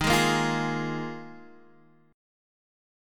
D#sus2sus4 chord